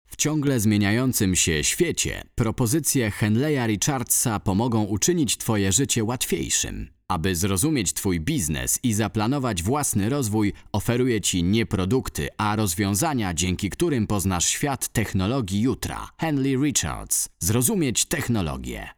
Kein Dialekt
Sprechprobe: Industrie (Muttersprache):